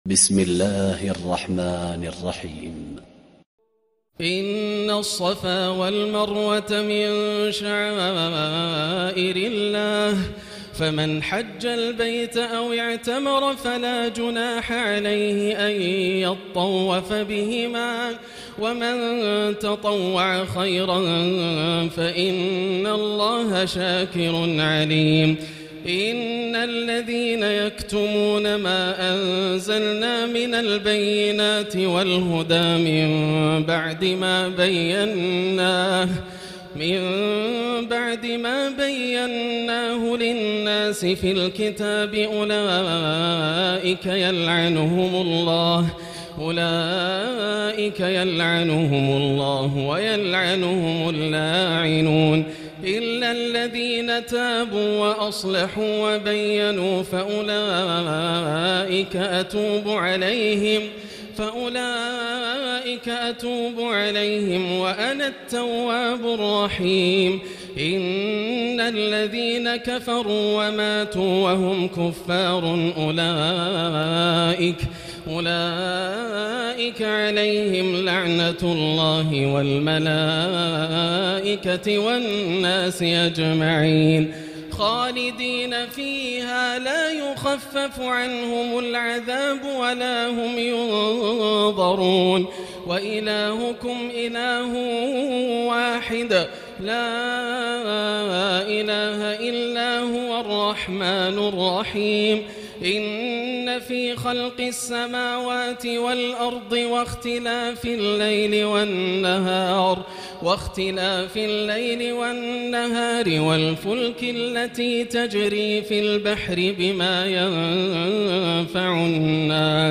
الليلة الثانية تلاوة من سورة البقرة 158-225 > الليالي الكاملة > رمضان 1439هـ > التراويح - تلاوات ياسر الدوسري